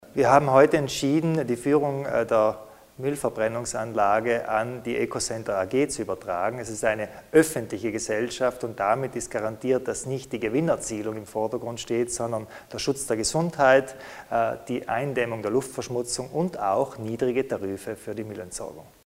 Landeshauptmann Kompatscher zur Führung der neuen Müllverwertungsanlage in Bozen